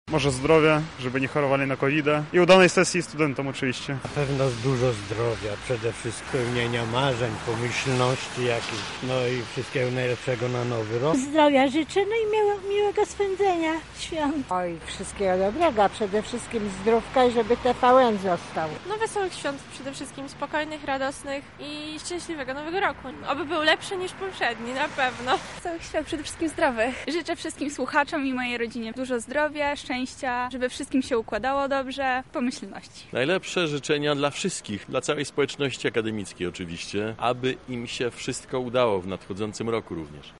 [SONDA] Czego życzą lublinianie słuchaczom Akademickiego Radia Centrum?
Zapytaliśmy mieszkańców Lublina, jakie życzenia chcieliby złożyć naszym słuchaczom:
sonda